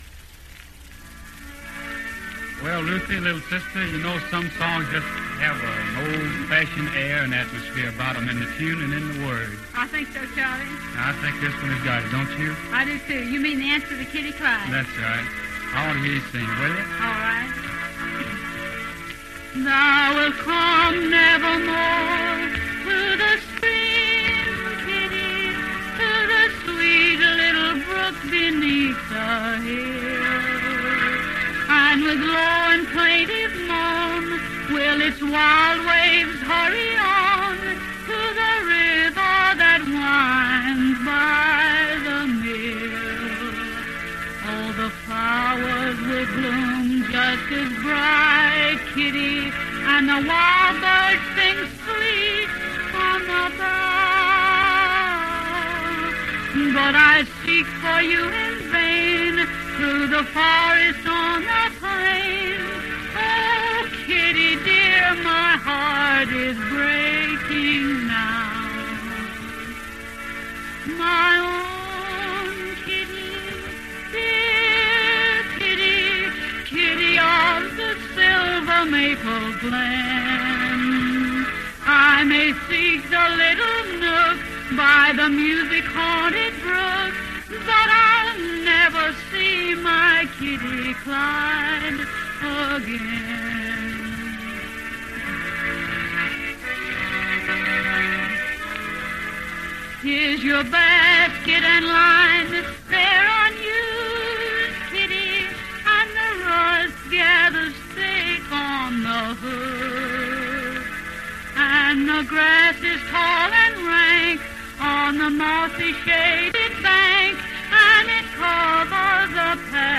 In an attempt to decontaminate my computer and mind after previous item, here's a heavenly little song found in an unexpected place, among a collection of 1939 news in OTR stuff.
Performed by the Pickard family on XERA, the Brinkley station.